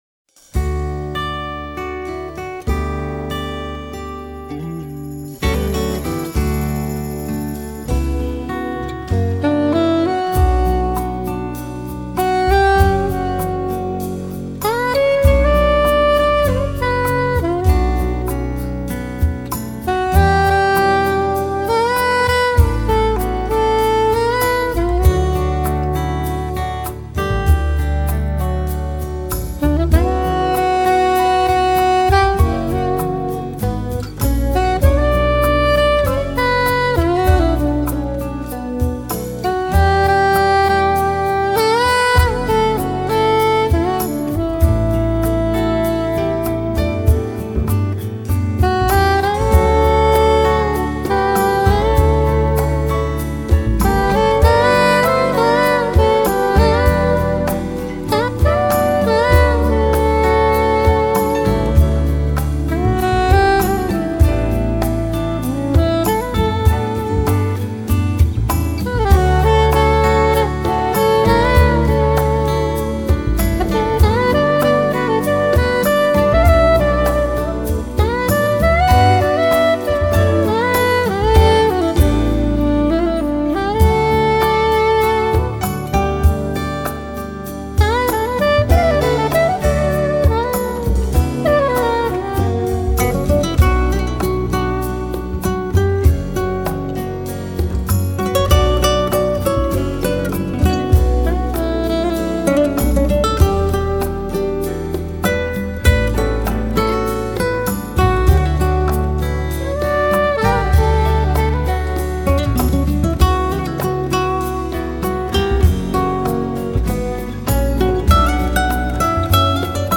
风格：Smooth Jazz